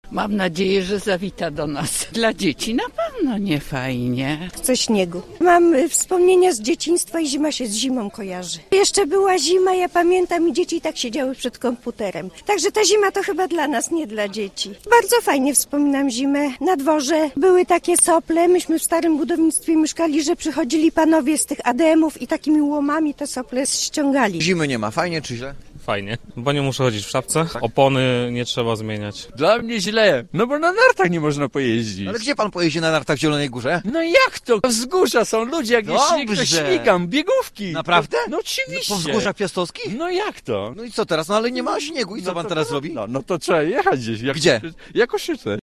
O to pytaliśmy dzisiaj w Polowym Studiu Radia Zielona Góra.
Zdania zielonogórzan na temat zimy są podzielone: